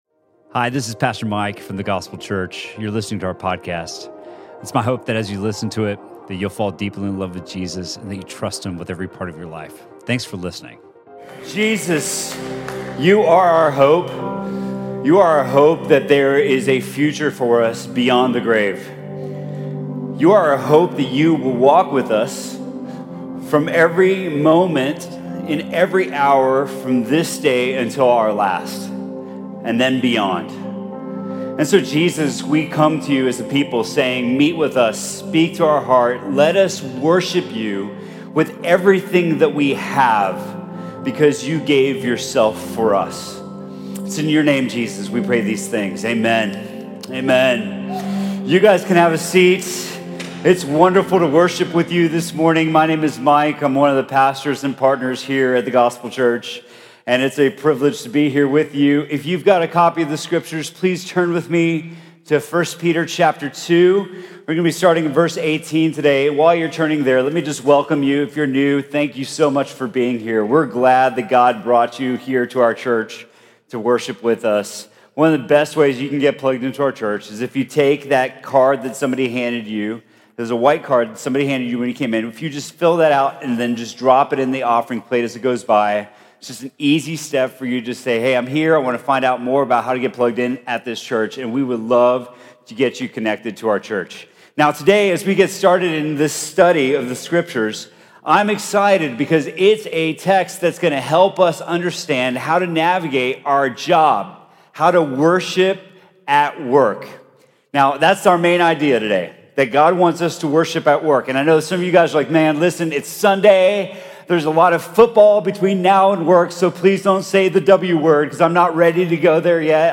Sermon from The Gospel Church on September 16th, 2018.